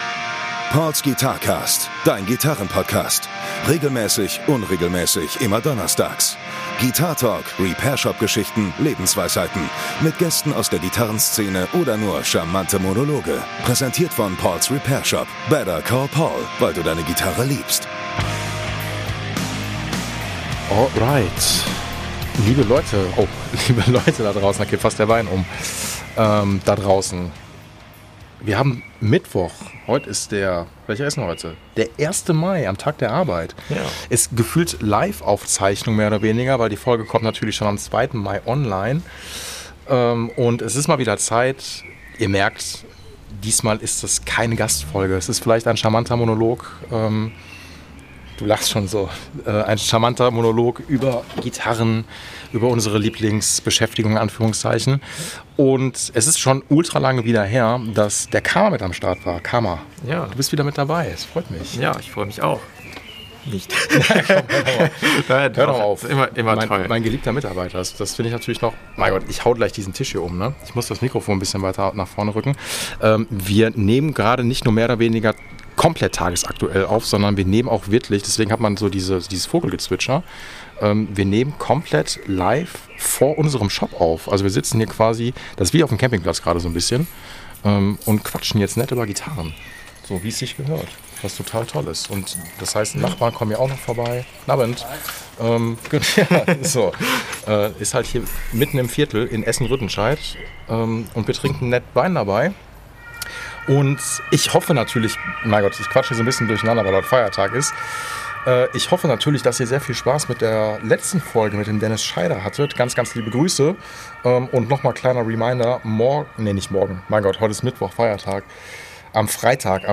Wie sehr haben wir bei dem Gitarrengott gesündigt? Wir quatschen drüber! Die ein oder andere Repairshopgeschichte darf natürlich auch nicht fehlen.